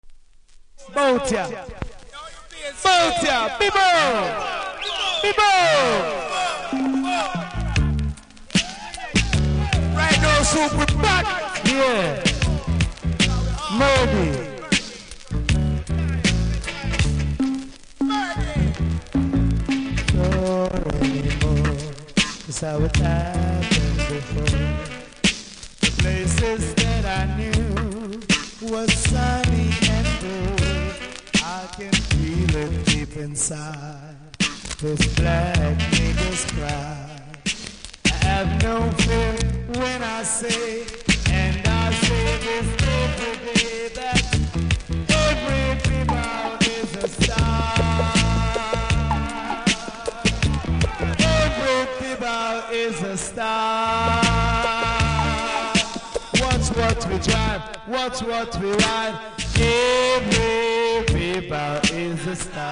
当時の貴重なライブ音源♪　往年の定番リズムでラバダブ！
キズもノイズもそこそこありますが聴けます。
あまり高音域が入ってないので録音音源は高音上げて録音してあります。